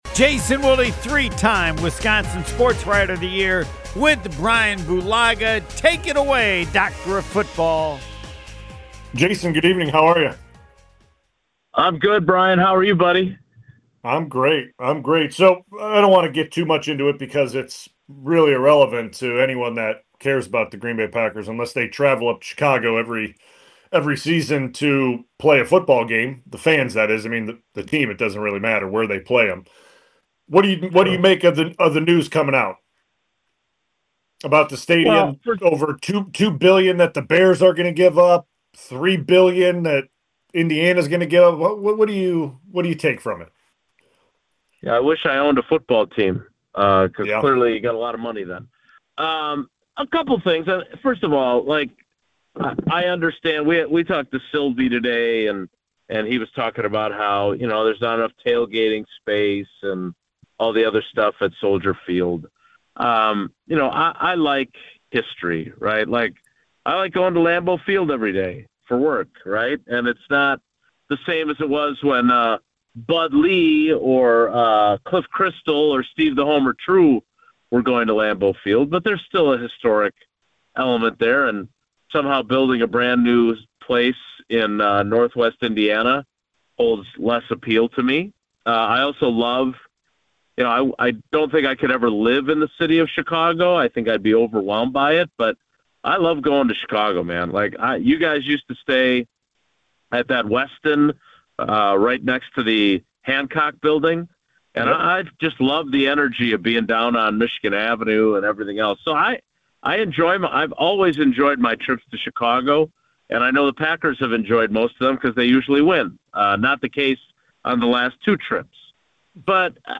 The conversation started with reaction to reports that the Chicago Bears are exploring building a stadium in Indiana and what that could mean for the rivalry. From there, the focus shifted to Green Bay’s offseason priorities — specifically the offensive line.